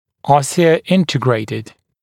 [ˌɔsɪə’ɪntɪgreɪtɪd][ˌосиэ’интигрэйтид]внедренный в кость, остеоинтергрироанный